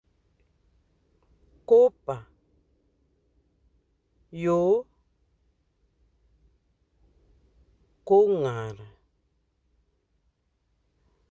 Accueil > Prononciation > oo > oo